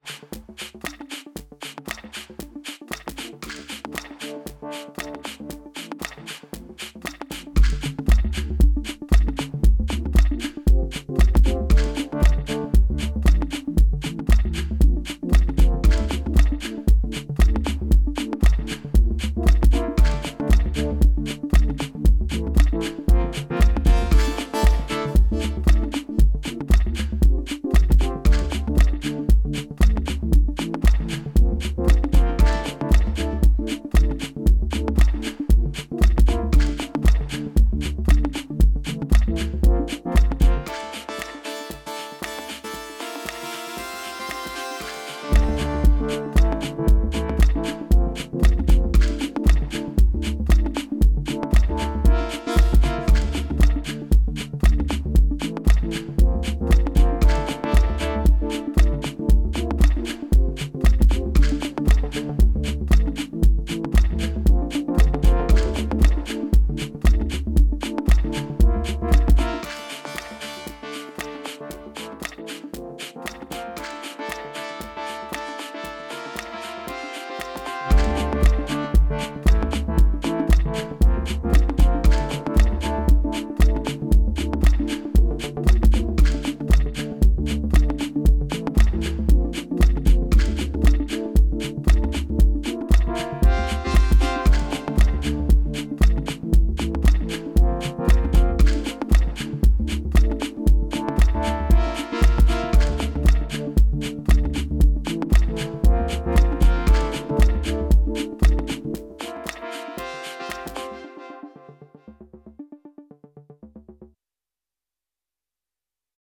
OT drummachine + 2 tracks of 707.